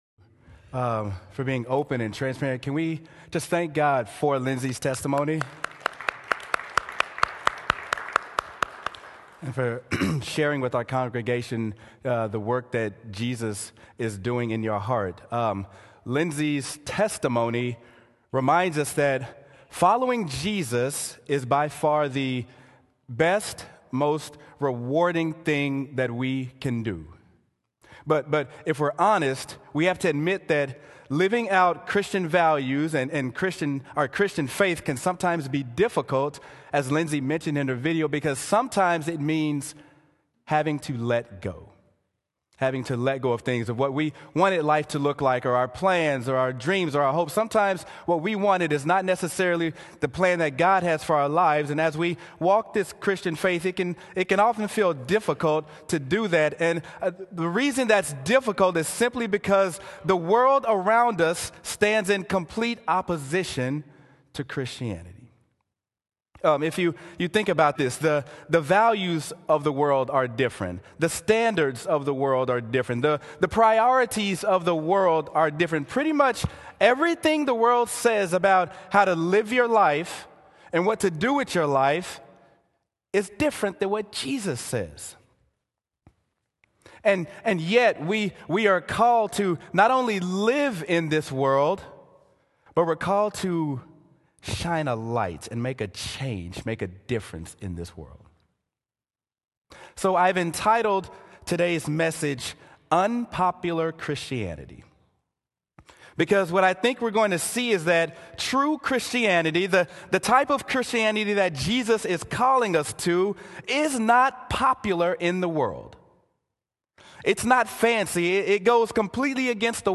Sermon: Mark: Unpopular Christianity
sermon-mark-unpopular-christianity.m4a